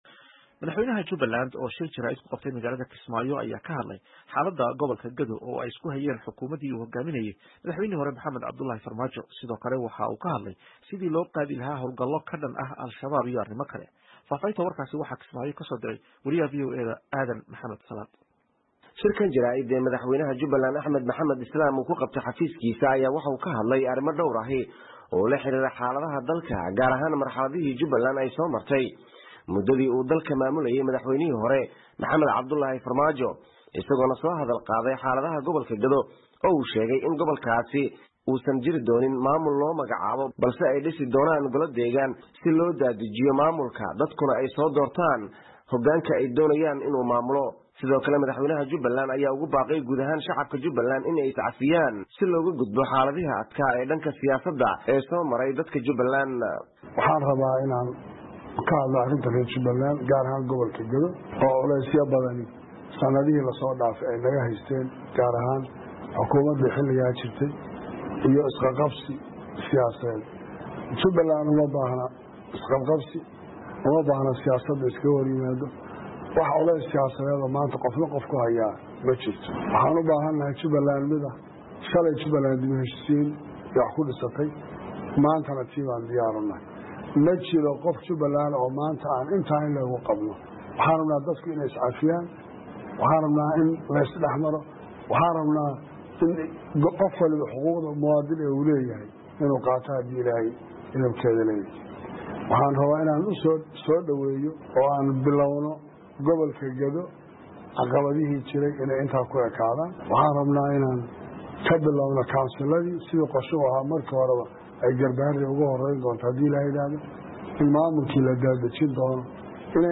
Madaxweynaha Jubbaland Axmed Madoobe oo shir jaraa'id ku qabtay magaalada Kismaayo ayaa ka hadlay xaalada gobolka Gedo oo ay isku hayeen dowladdii madaxweynihii hore Maxamed Cabdullahi Farmaajo, isaga oo sidoo kale ka hadlay sidii loo qaadi lahaa howlgalo ka dhan ah Alshabaab iyo arrima kale.